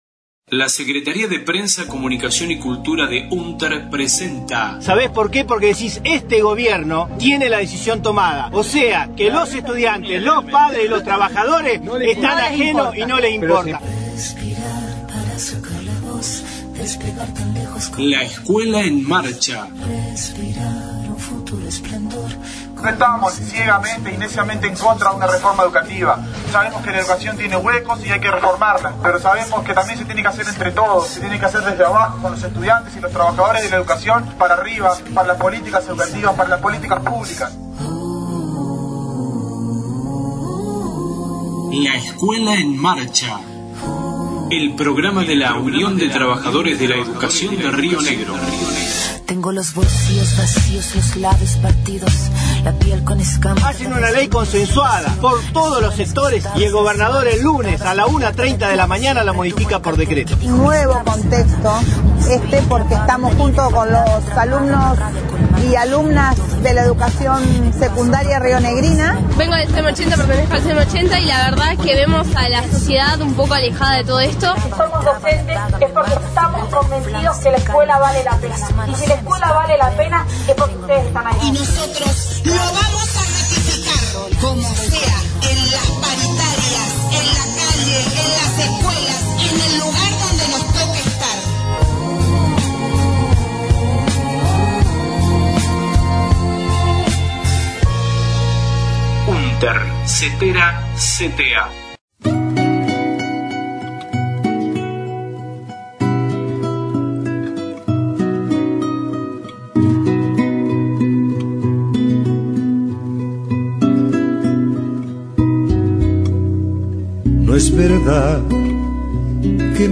La Escuela en Marcha Radio La Escuela en Marcha Nivel Inicial